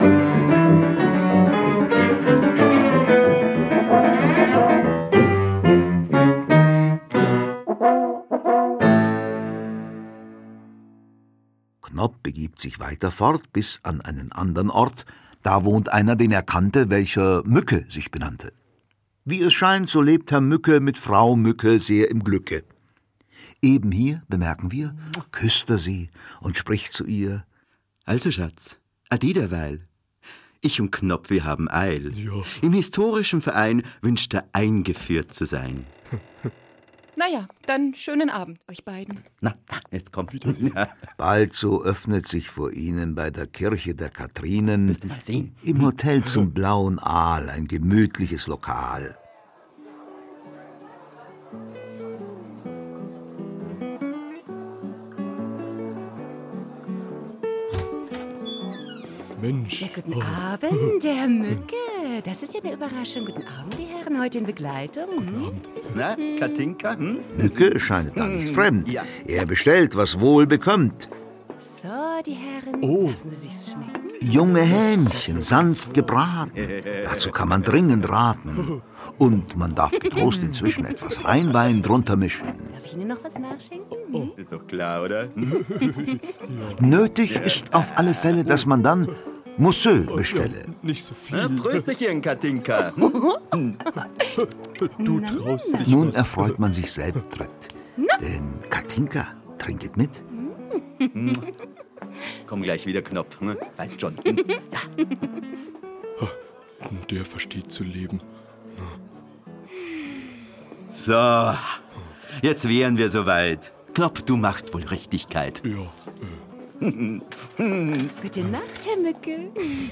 Die Knopp-Trilogie - Wilhelm Busch - Hörbuch